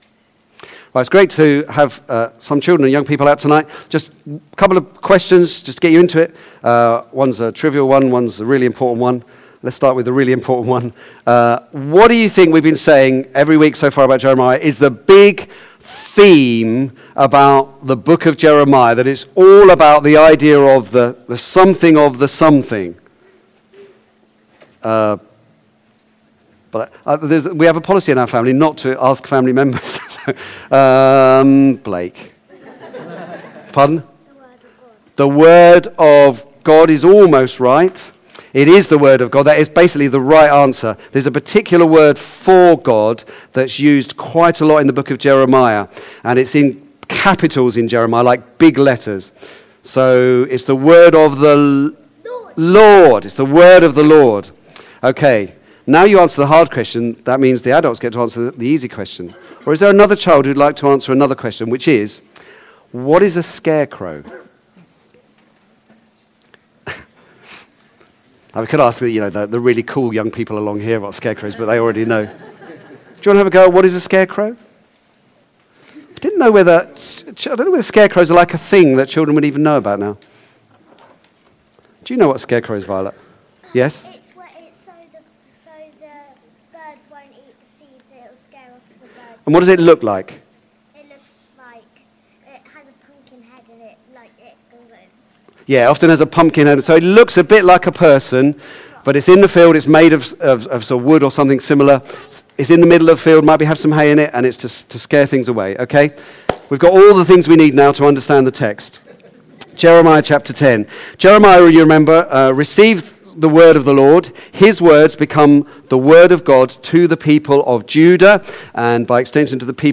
Back to Sermons God and idols